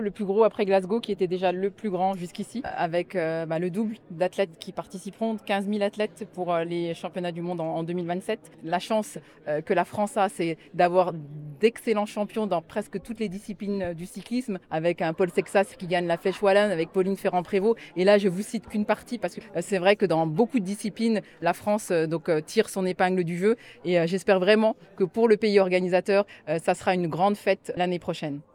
Les principaux acteurs de l’organisation, les élus ainsi que de nombreux athlètes se sont réunis, ce jeudi 23 avril 2026, au Congrès Impérial, à Annecy, pour présenter les contours de cet évènement spectaculaire. 14 sites ont été retenus et confirmés sur le département, avec le vélodrome de Saint-Quentin-en-Yvelines.